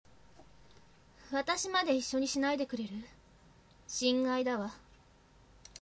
サト-sato-　（♀）　14歳
ヤエとは正反対のクール人間。